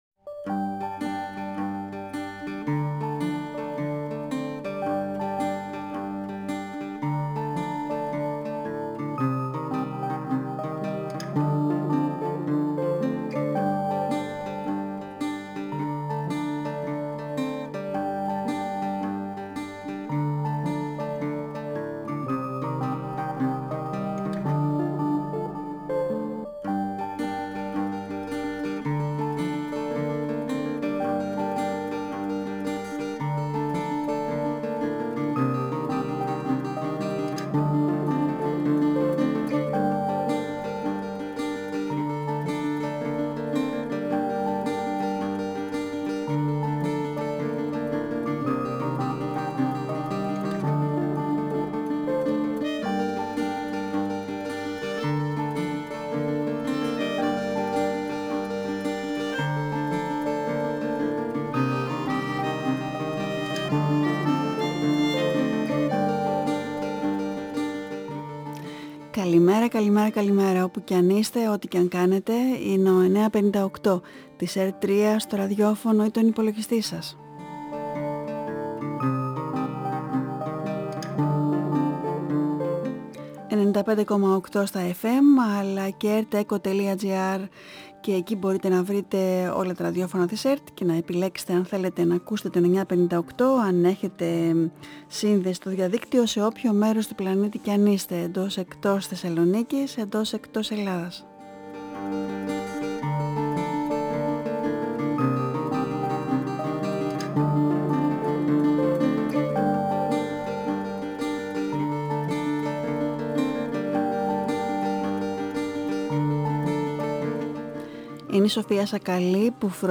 Συνέντευξη με τον Σπύρο Γραμμένο | Καλημέρα – 958fm | 28 Σεπτεμβρίου 2022
Η συνέντευξη πραγματοποιήθηκε την Τετάρτη 28 Σεπτεμβρίου 2022 στην εκπομπή “Καλημέρα” στον 9,58fm της ΕΡΤ3.